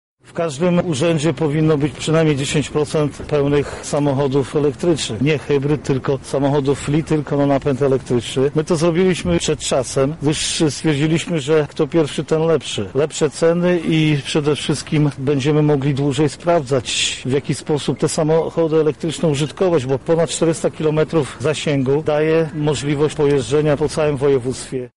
Samochody elektryczne to przyszłość – mówi marszałek województwa lubelskiego Jarosław Stawiarski: